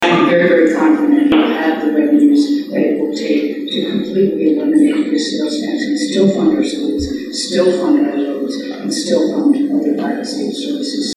The Kansas Farm Bureau hosted its annual meeting Sunday, with Gov. Laura Kelly as the key guest speaker.